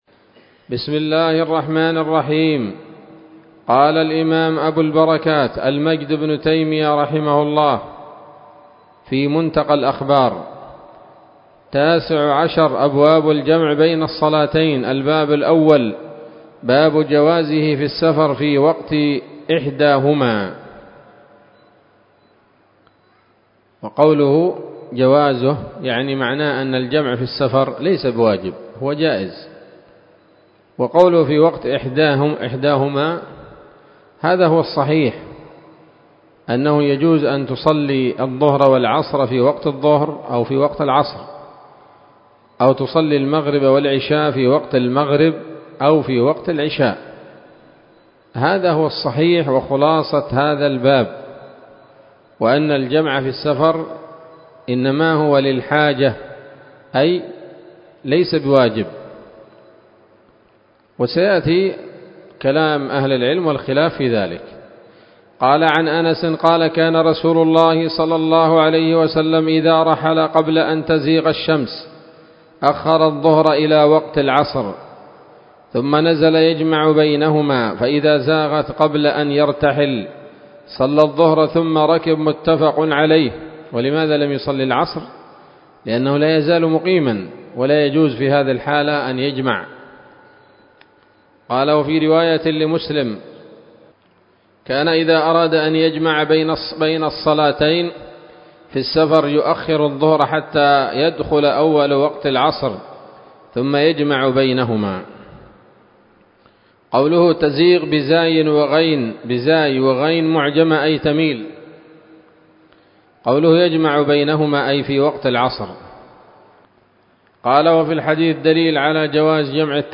الدرس الأول من ‌‌‌‌أَبْوَاب الجمع بين الصلاتين من نيل الأوطار